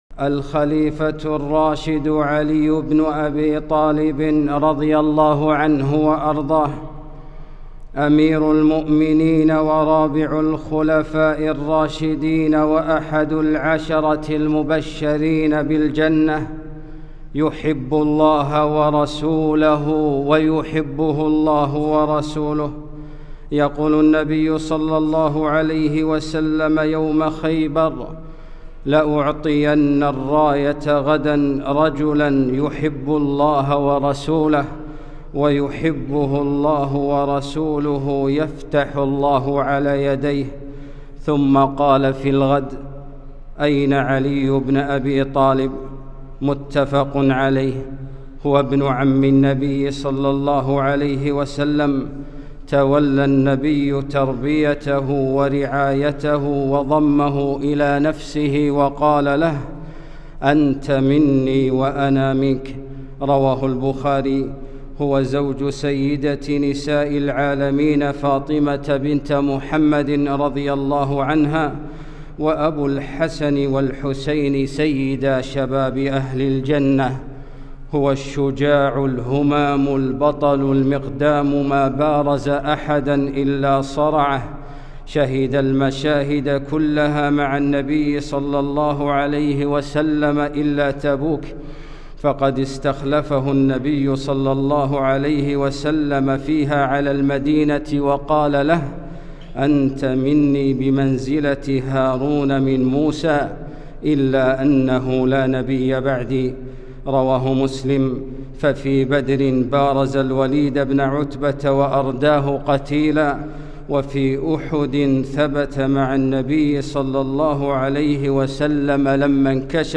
خطبة - الخليفة الراشد